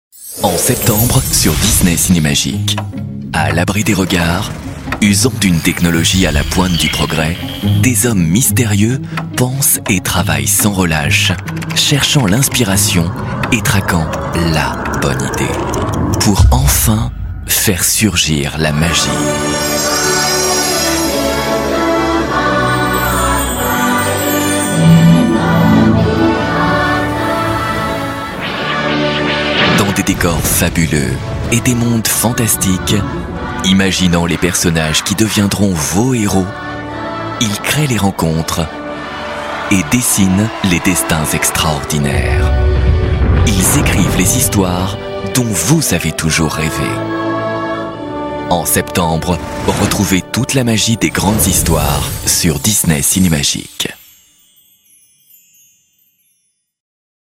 Genre : voix off.